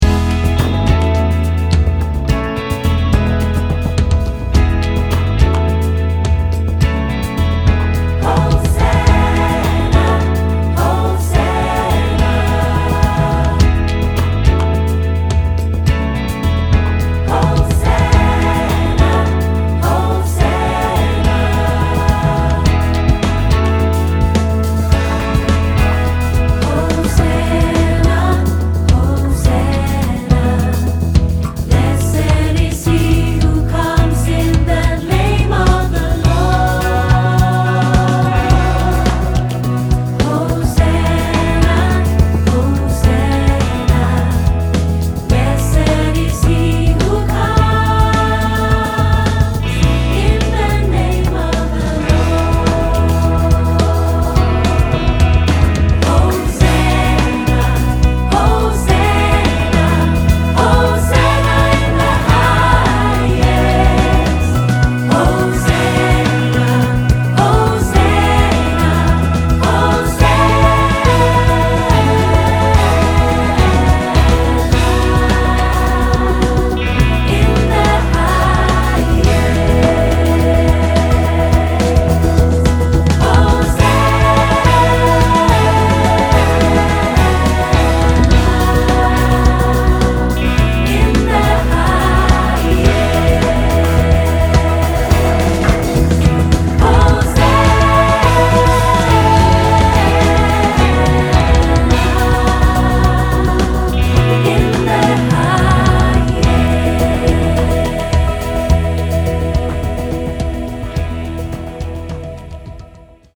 Hosanna_korter - Gospelkoor Or Tamim